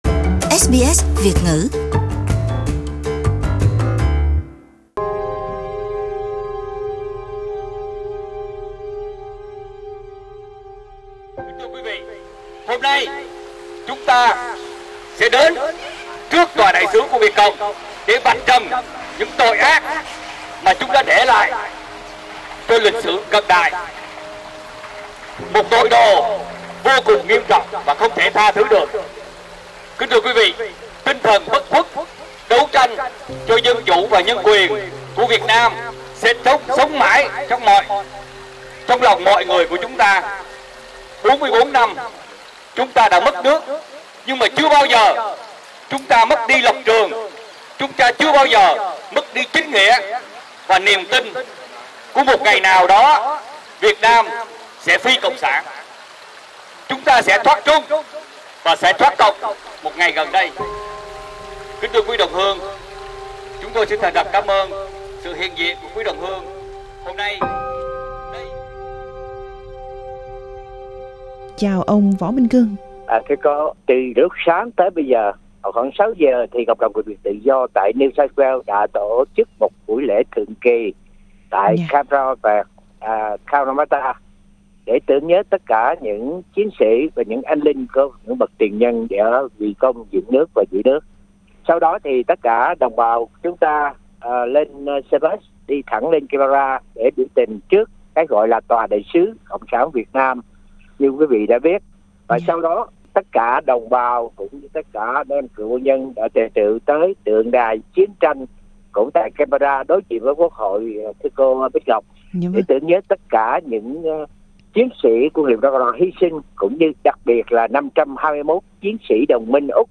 hai trong số hàng ngàn người tham gia biểu tình trả lời phỏng vấn của SBS Việt ngữ.